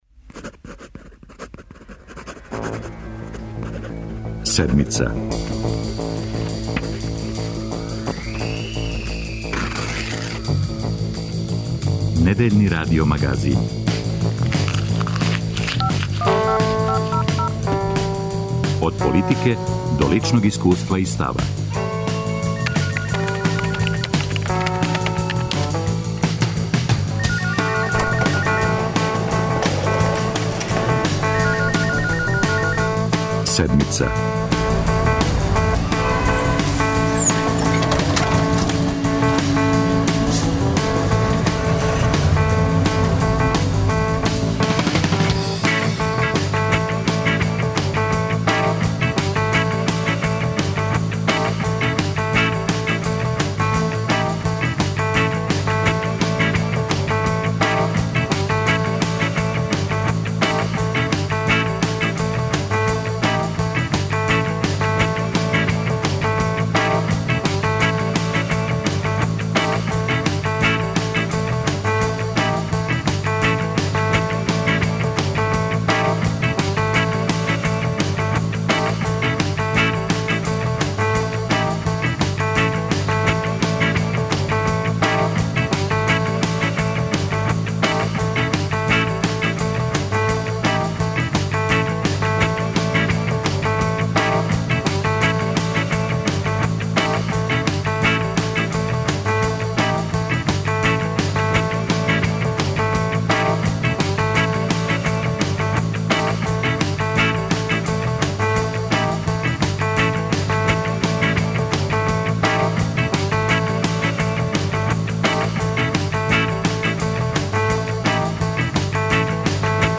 преузми : 29.81 MB Седмица Autor: разни аутори Догађаји, анализе, феномени.